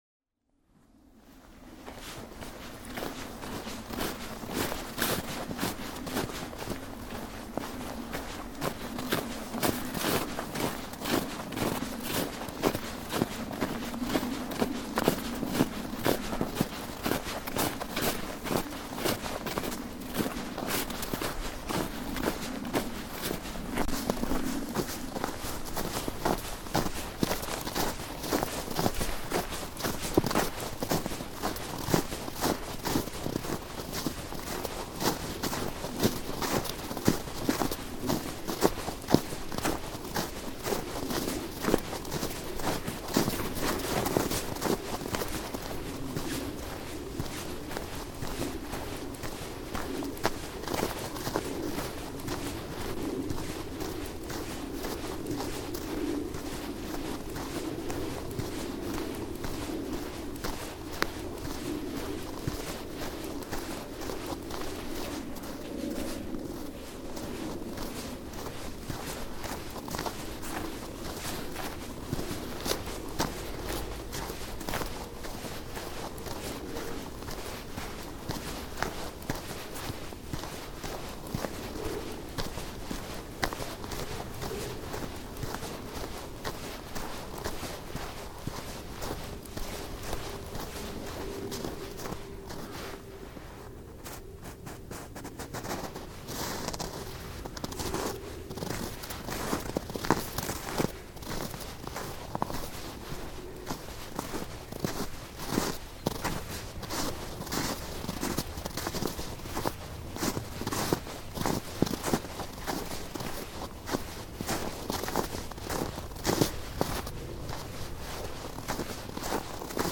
24 - Walking in Snow
This sound was recorded in 2010 and features the sounds of walking in snow. It was used to help create the soundscape for the Sonic Wallpaper piece made to accompany BADDA 4782 in the MoDA wallpaper collection